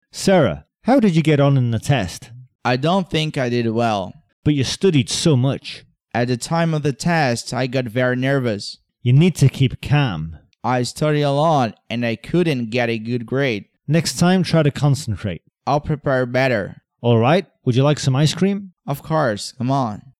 Conversações em Inglês para iniciantes